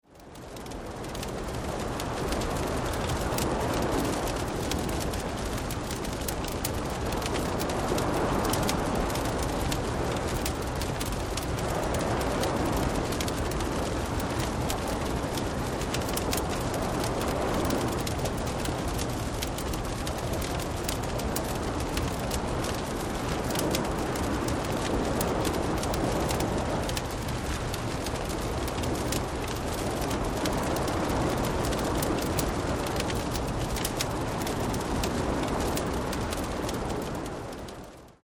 6 Stunden Kaminfeuer mit stürmischer See (MP3)
Bei Geräuschaufnahmen sind diese ebenfalls in 44.1 kHz Stereo aufgenommen, allerdings etwas leister auf -23 LUFS gemastert.
44.1 kHz / Stereo Sound
Hoerprobe-Kamin-Stuermisches-Meer.mp3